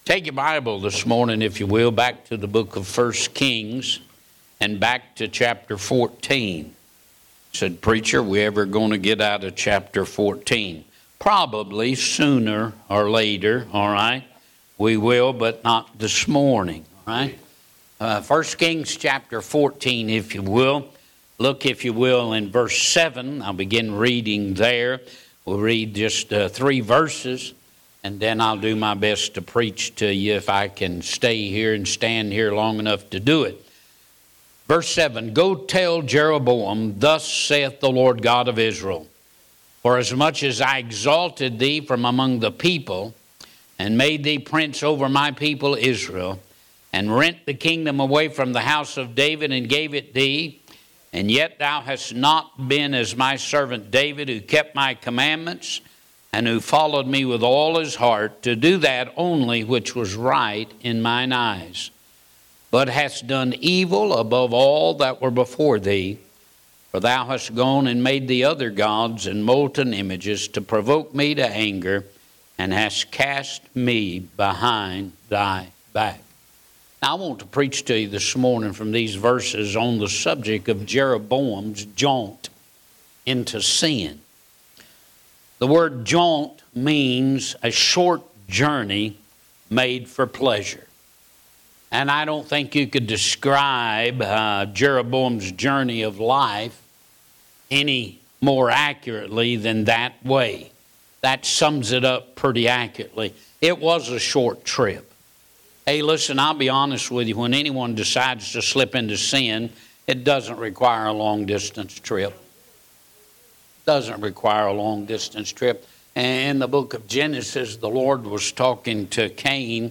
Website for First Baptist Church Haltom City, Fort Worth, Texas